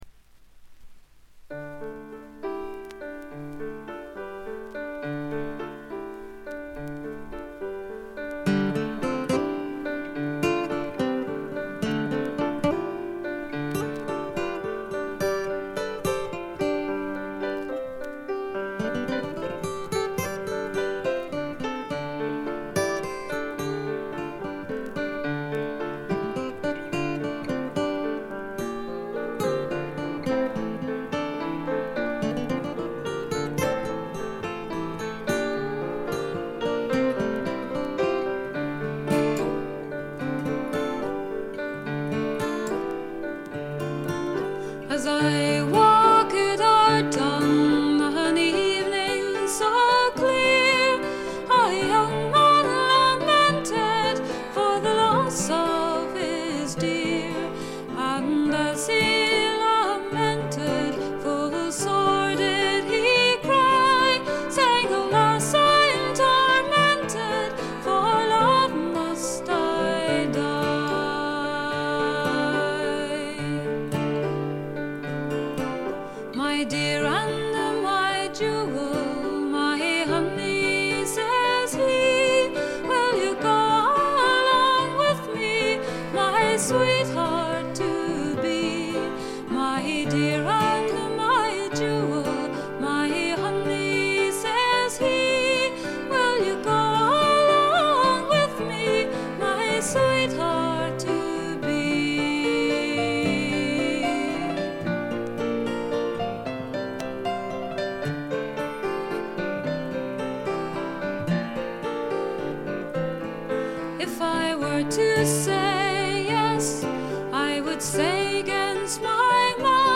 微細なチリプチがほんの少し。
試聴曲は現品からの取り込み音源です。
Acoustic Guitar
Bagpipes [Uilleann Pipes]
Vocals, Piano